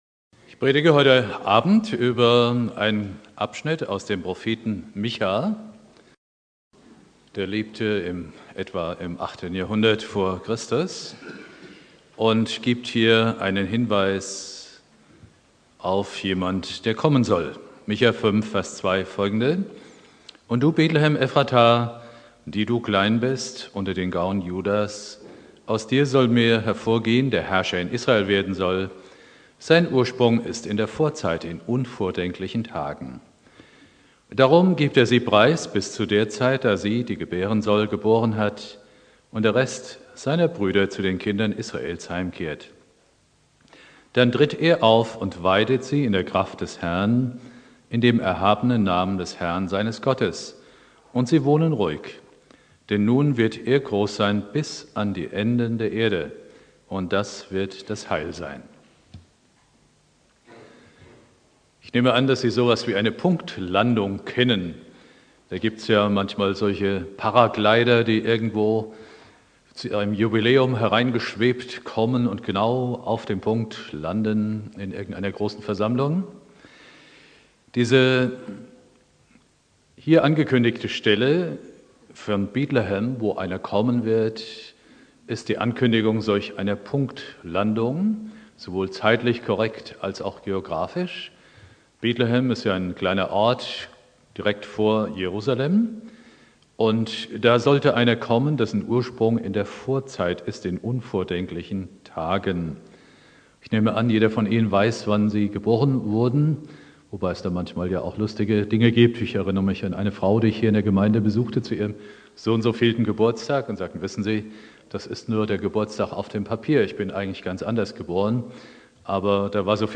Predigt
1.Weihnachtstag Prediger